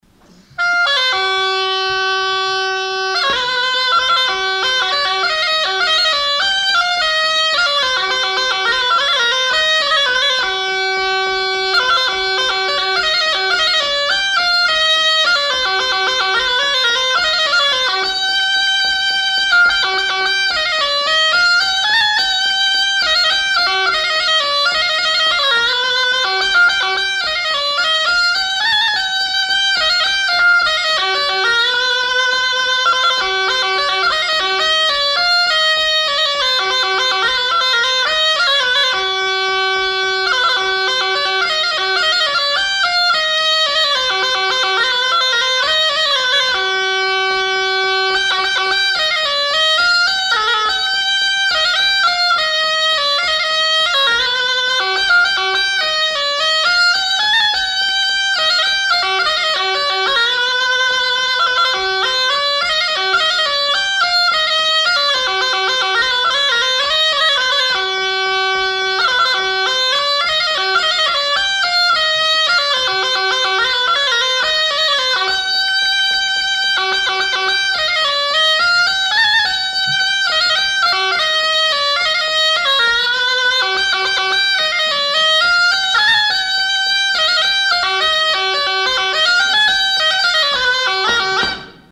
Lieu : Marvejols
Genre : morceau instrumental
Instrument de musique : cabrette
Danse : valse